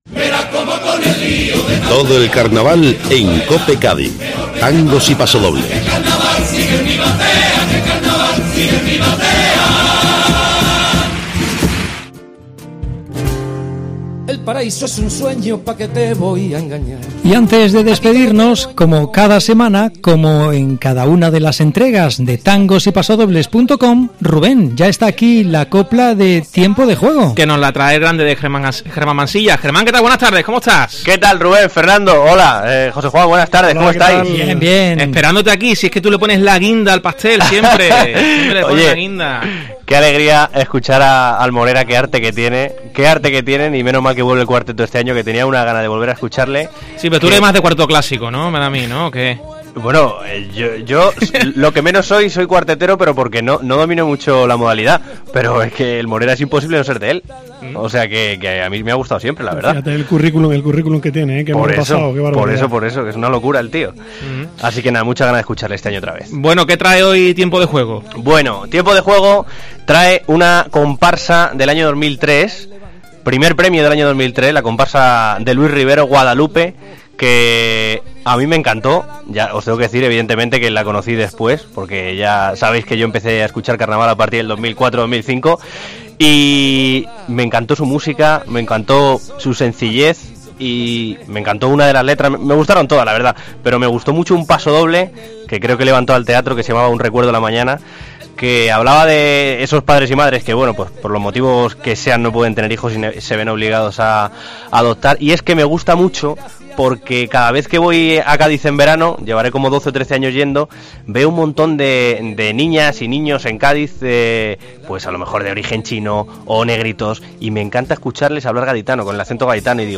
pasodoble
primer premio en comparsas de 2003